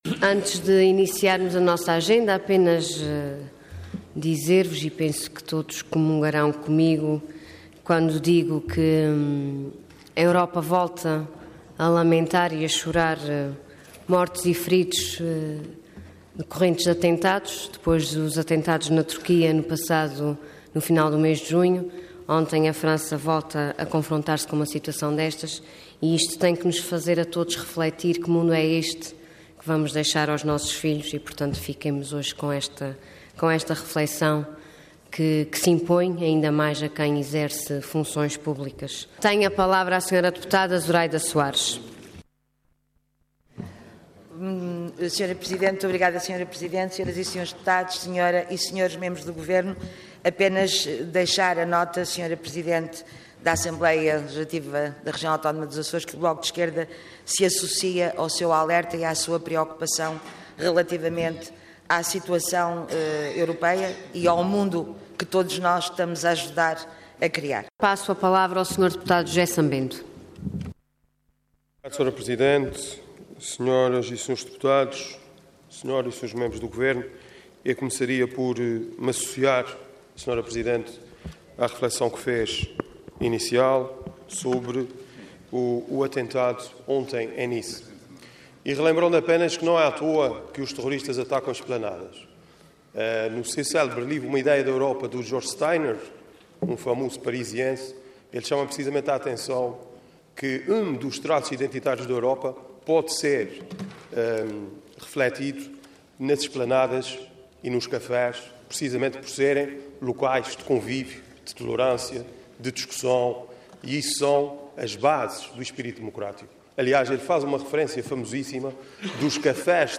Parlamento online - Declaração da Presidente da ALRAA, dos Grupos Parlamentares do PS e PSD e Representações Parlamentares do BE e PCP sobre o Atentado Terrorista de Nice
Intervenção Orador Ana Luísa Luís Cargo Presidente da Assembleia Regional